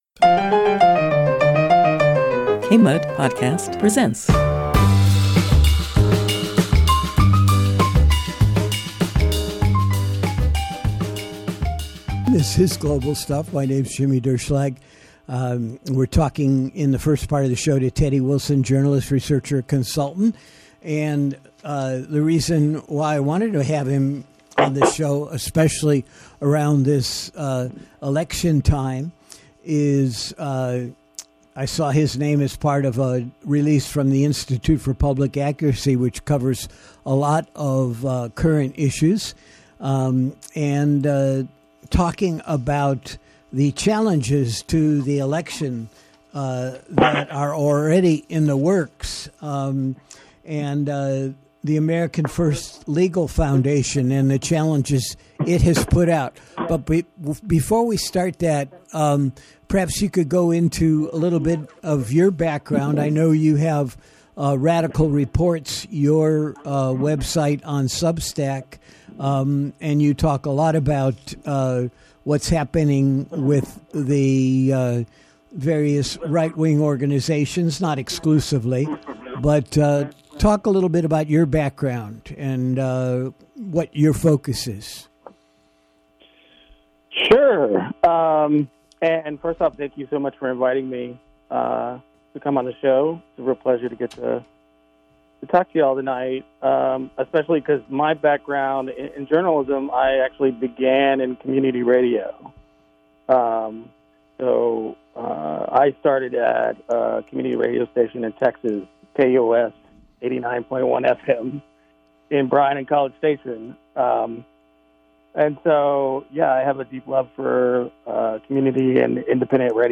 a monthly guest driven talk show for over 20 years.